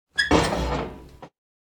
open-door.ogg